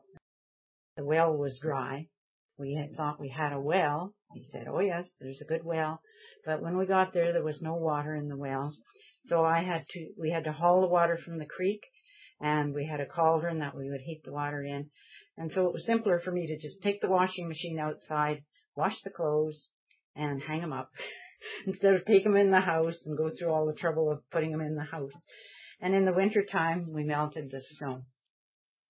Washing Clothes Outside - Sound Clip